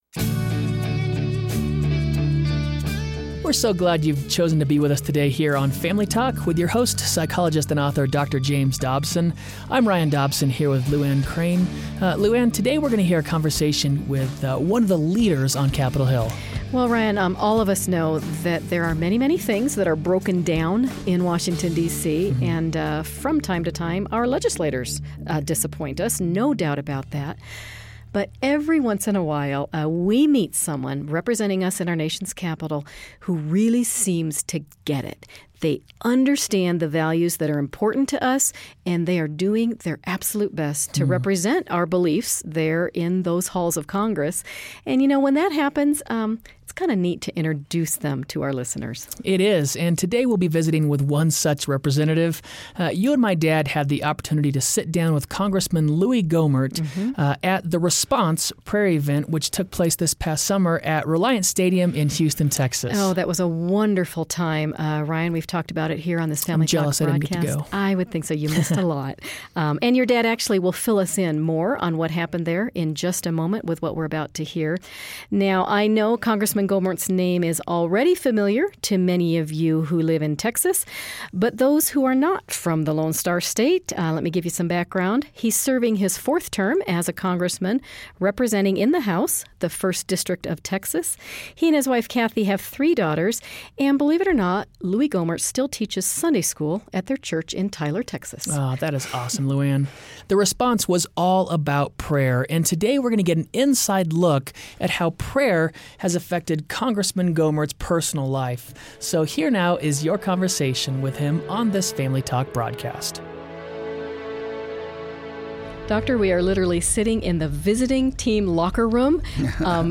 On today's program, hear Texas Congressman Louie Gohmert explain how prayer saved his mother's life, and how it could provide a path of national renewal for the United States of America.